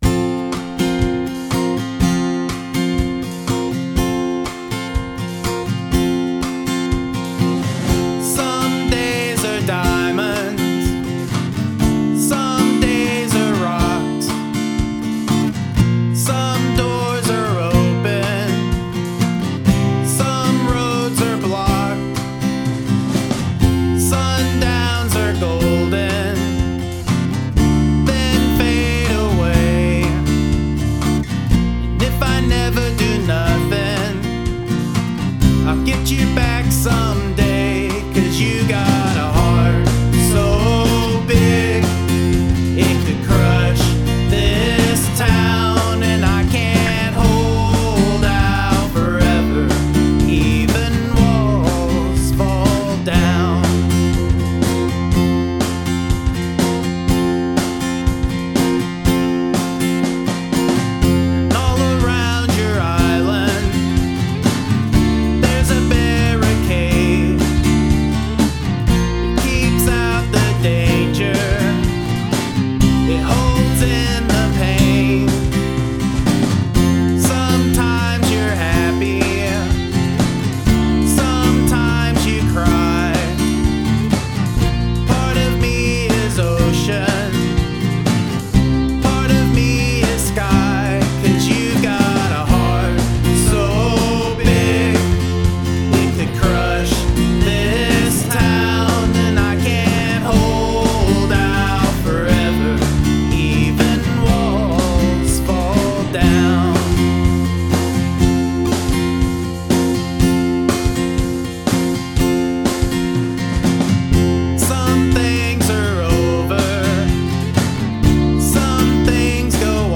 Sounds like live-music.
Strums along beautifully and has a nice fresh feel about it.